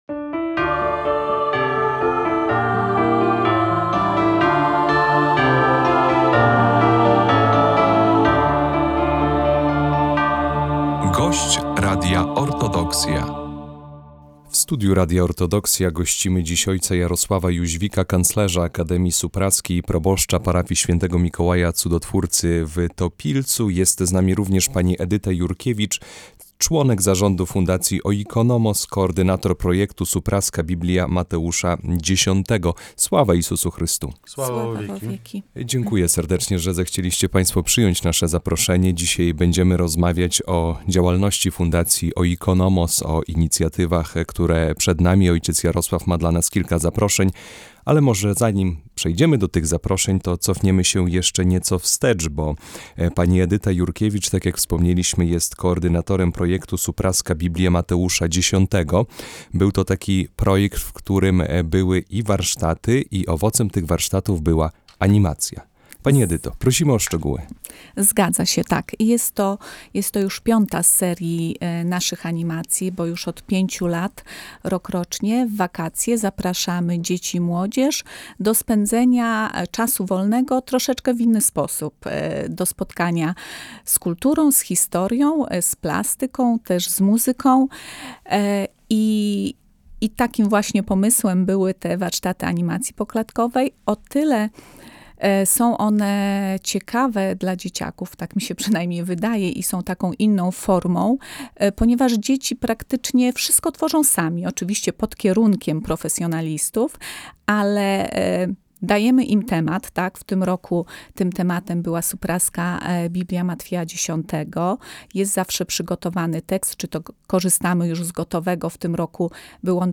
O działalności fundacji Oikonomos, a także o najbliższych planach, inicjatywach i zaproszeniach rozmawialiśmy z naszymi gośćmi